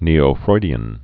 (nēō-froidē-ən)